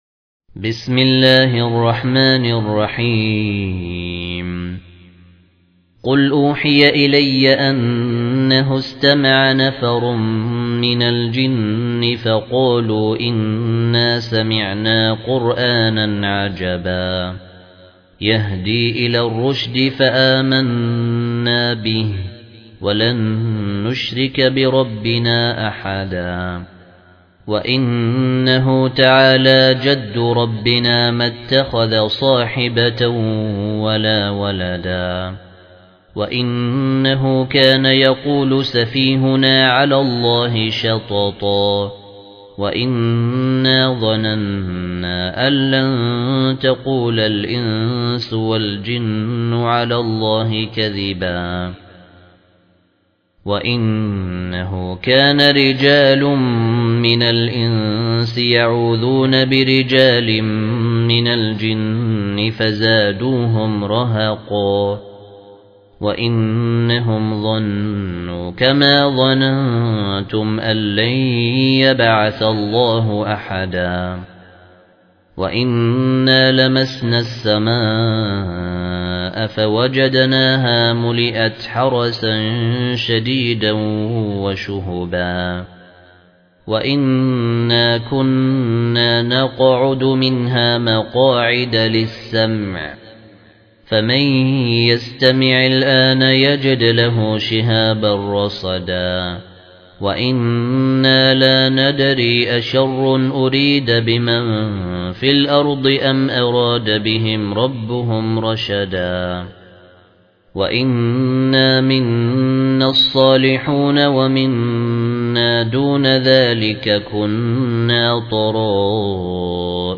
المصحف المرتل - الدوري عن أبي عمرو البصري